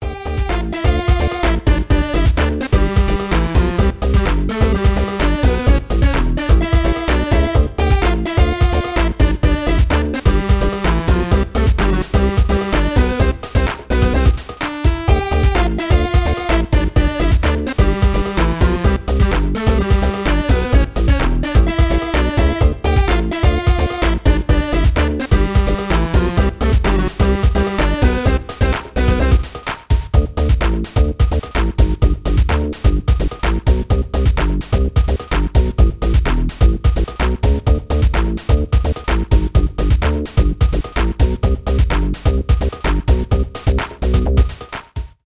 электронные